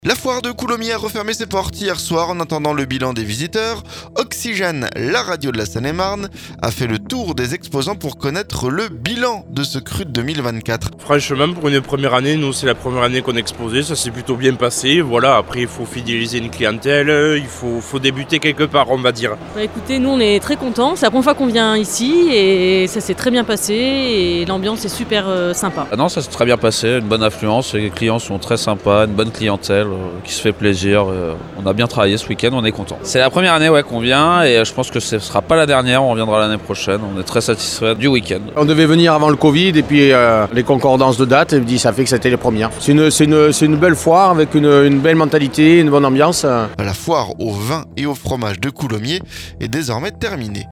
La Foire de Coulommiers a refermé ses portes hier soir. En attendant le bilan des visiteurs, Oxygène, la radio de la Seine-et-Marne a fait le tour des exposants pour connaître leur bilan sur ce cru 2024.